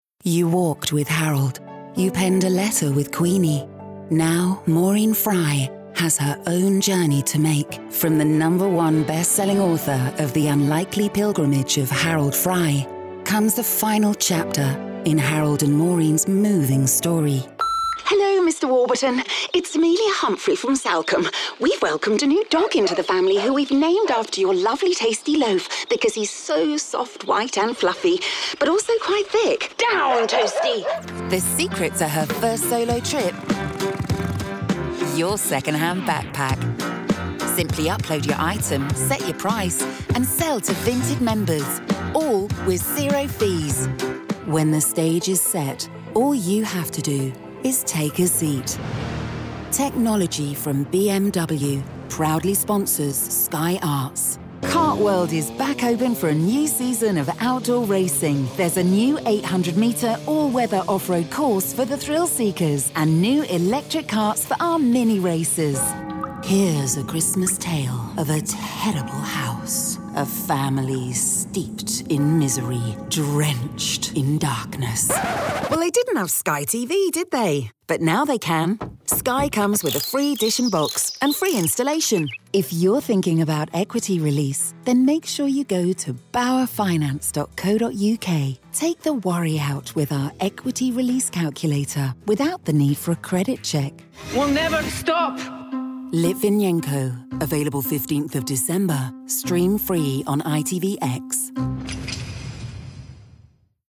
Multi-Nominated Professional British Voiceover. Clear, Contemporary, Confident.
Commercial Reel
Natural RP accent, can also voice Neutral/International and character.
Broadcast-ready home studio working with a Neumann TLM 103 mic.